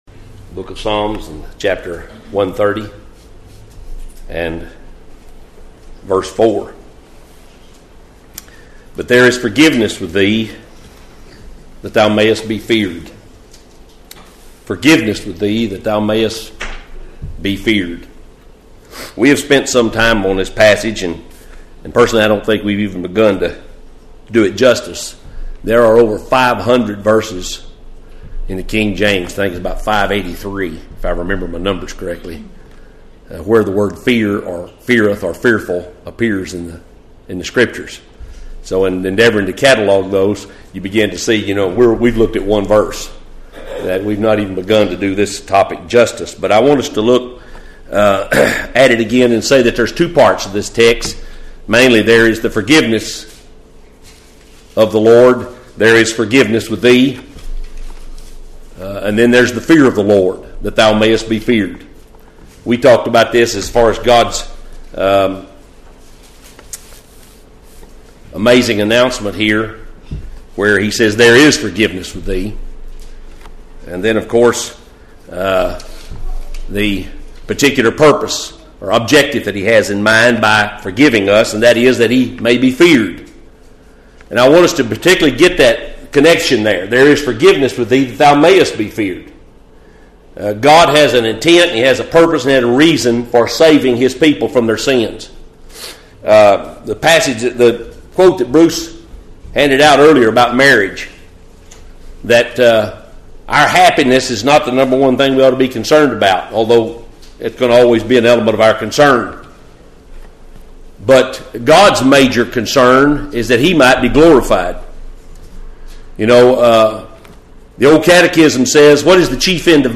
Audio Sermons | Baptist Standard Bearer, Inc.